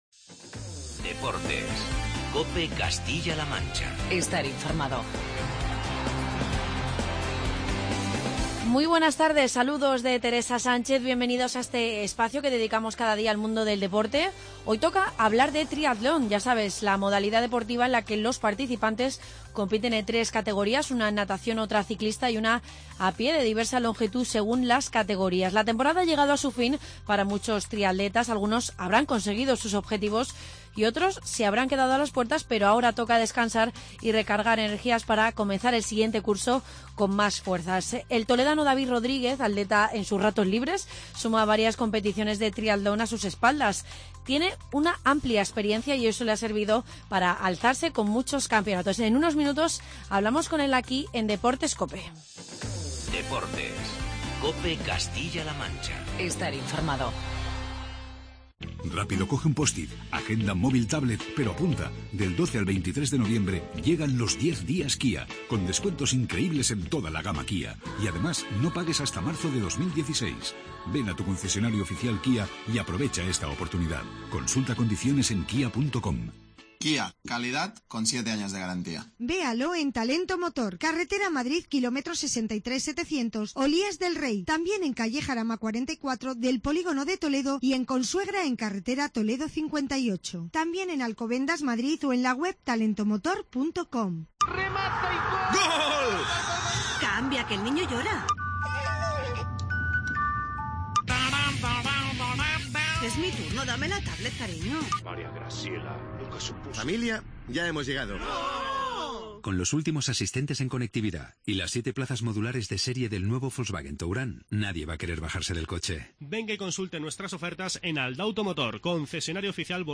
Charlamos con el triatleta